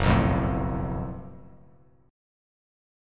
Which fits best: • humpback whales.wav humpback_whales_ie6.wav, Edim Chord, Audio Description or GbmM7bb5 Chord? GbmM7bb5 Chord